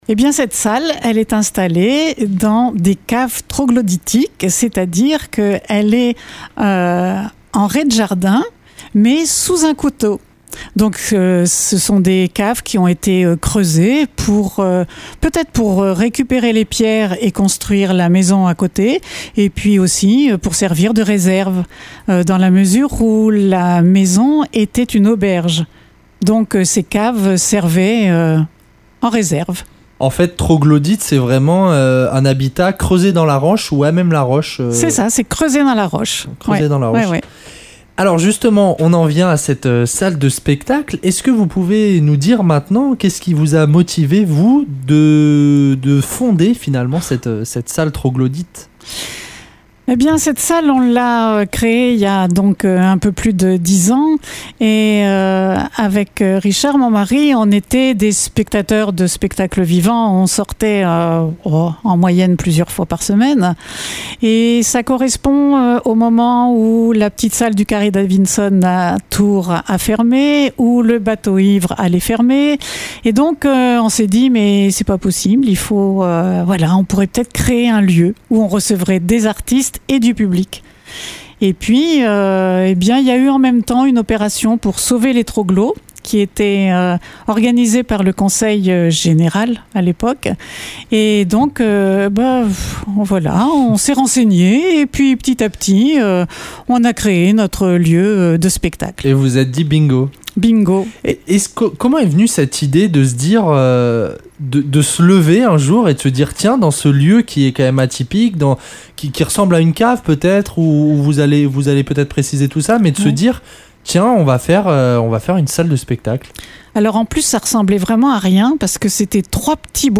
Interview.